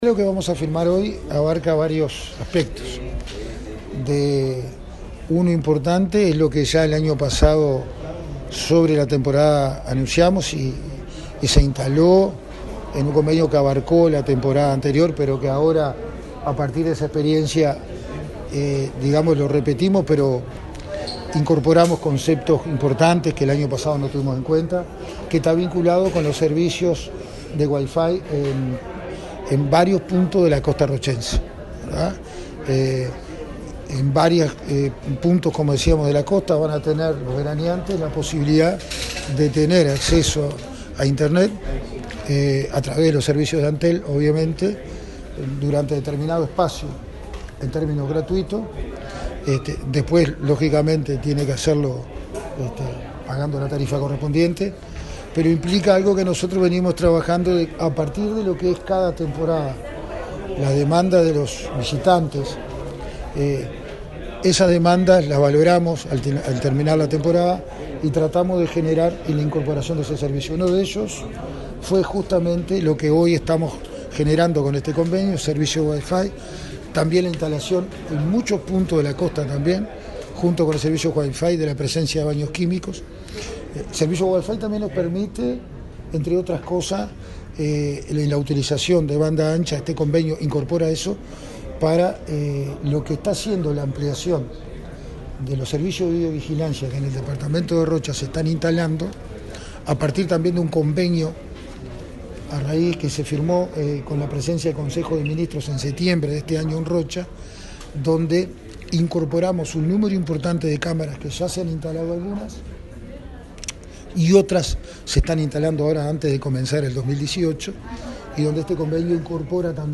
Escuche a Pereyra aquí:
Aníbal-Pereira-Intendente-de-Rocha.mp3